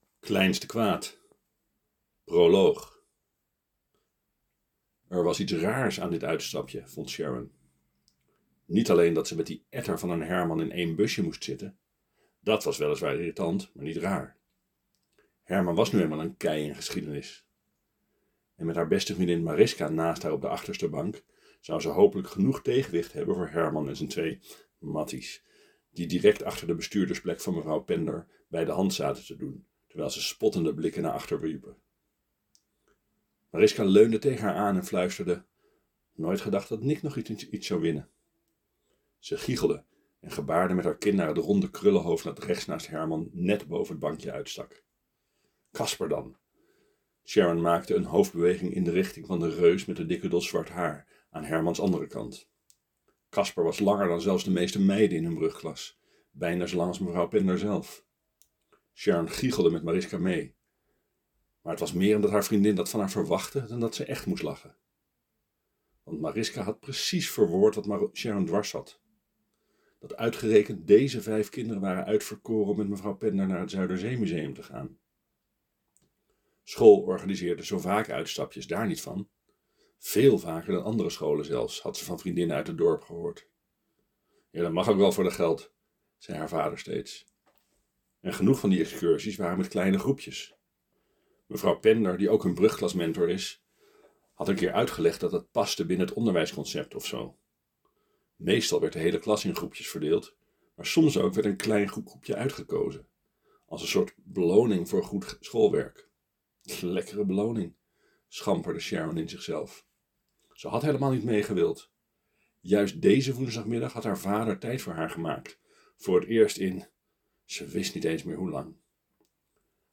Kleinste-kwaad-proloog.mp3